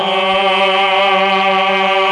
RED.CHOR1 13.wav